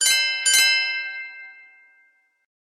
SMS_BellNotice.ogg